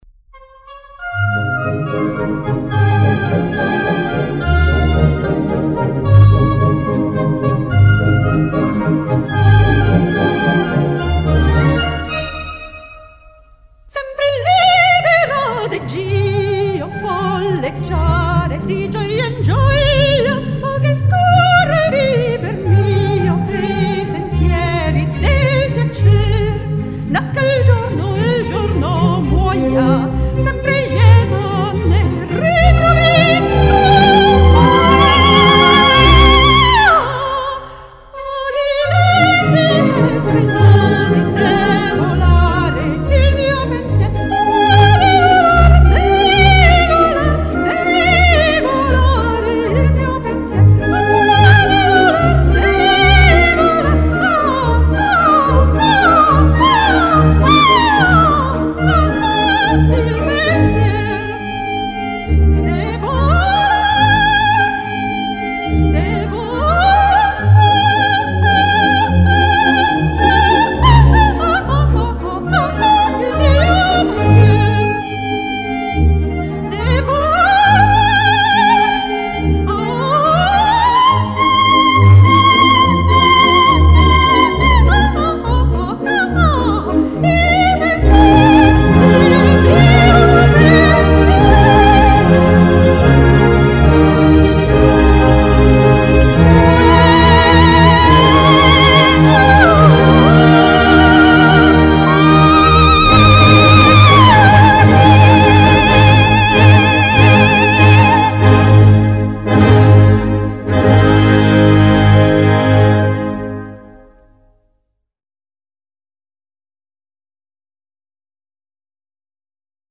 Diplomata come soprano lirico di coloritura, oltre al repertorio tradizionale operistico soprattutto verdiano e mozartiano con il quale ha svolto un’intensa attività in Italia e all’estero (Giappone, Francia, Russia), ha partecipato a molti galà di operetta e musical.